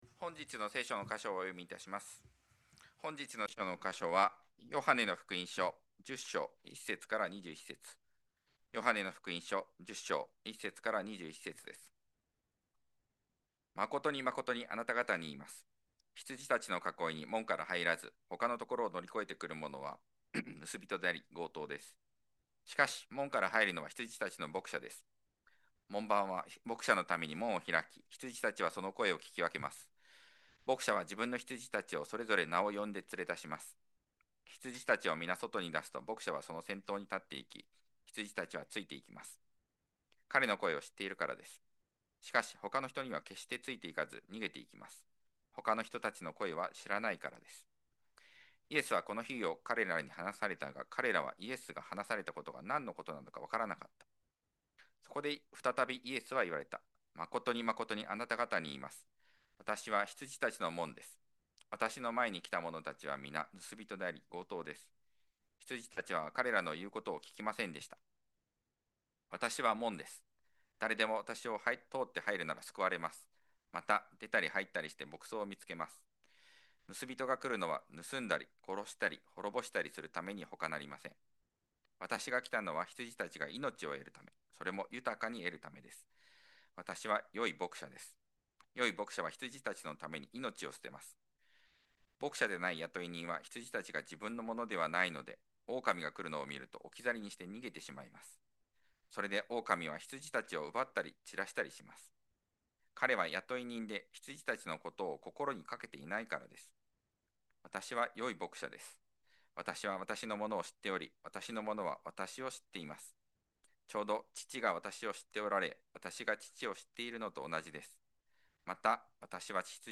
2026年3月15日礼拝 説教 「私は良い牧者です」 – 海浜幕張めぐみ教会 – Kaihin Makuhari Grace Church
録音トラブルにより、２９：５０から約１分間音声が流れません。